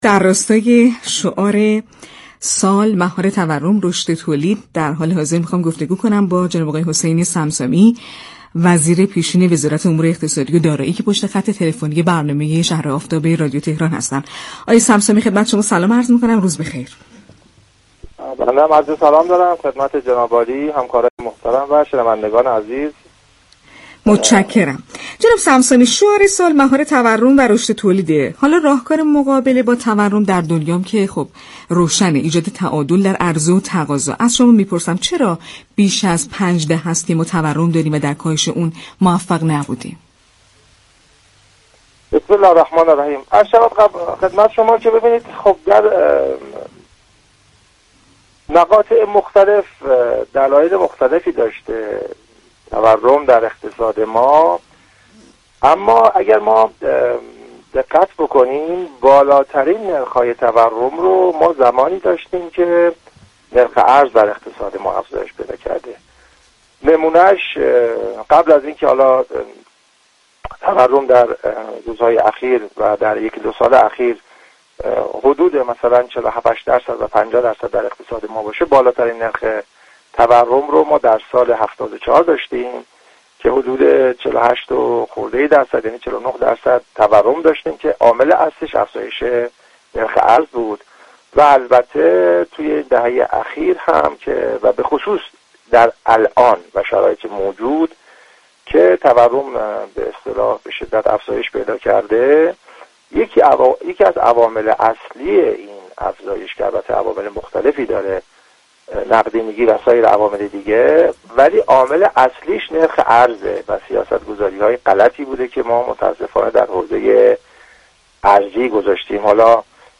به گزارش پایگاه اطلاع رسانی رادیو تهران، حسین صمصامی اقتصاددان و وزیر پیشین امور اقتصادی و دارایی در گفت و گو با «شهر آفتاب» رادیو تهران درخصوص مهار تورم اینگونه اظهار داشت: در مقاطع مخلتف رشد تورم در اقتصاد دلایل مختلفی داشت؛ اما به صورت كلی بالاترین نرخ تورم مربوط به زمانی است كه نرخ ارز افزایش داشته است.